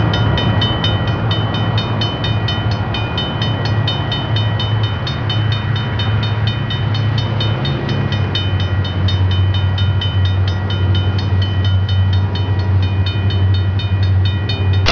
Traincars
TrainCars.wav